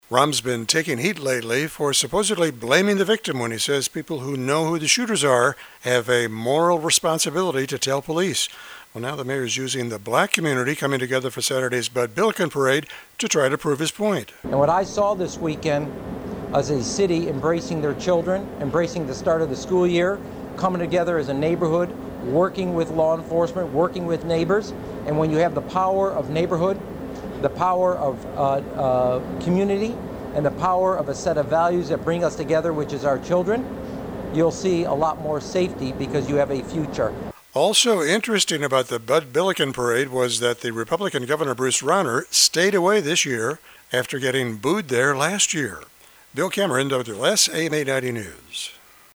WLS-AM News